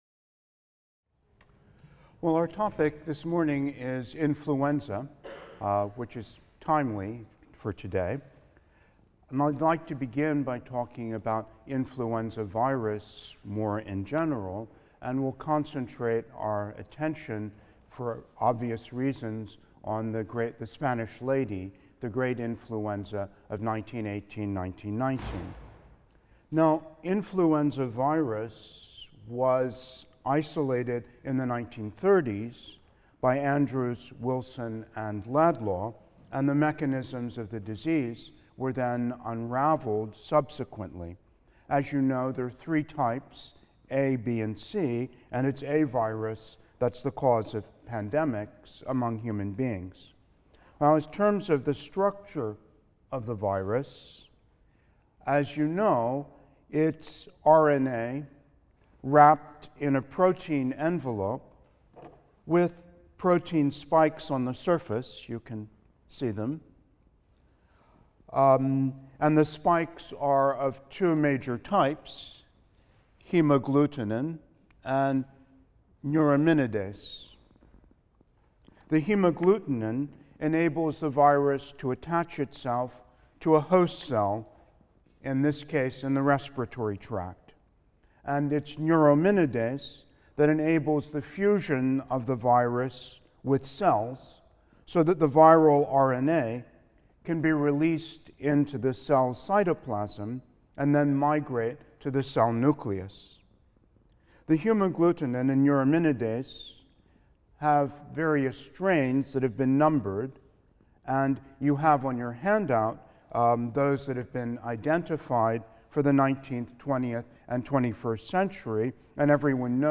HIST 234 - Lecture 20 - Pandemic Influenza | Open Yale Courses